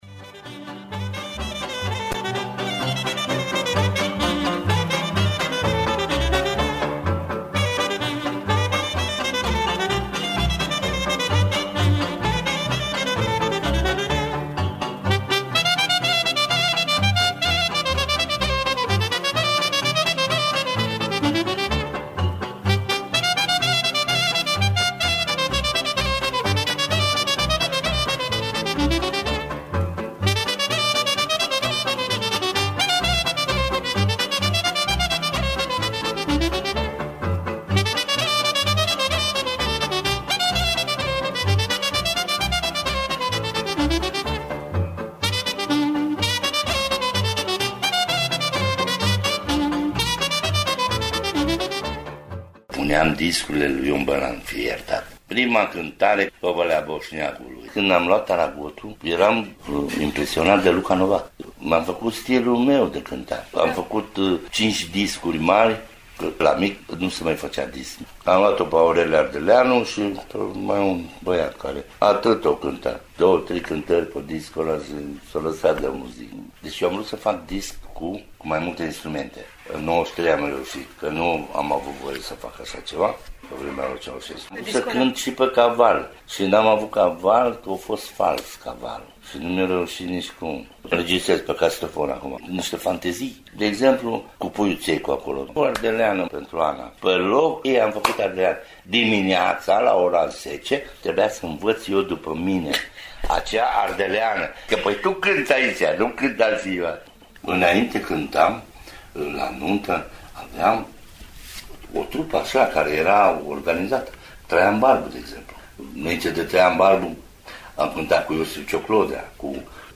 fragment din interviu, cu ilustrații:
insert-cu-melodii.mp3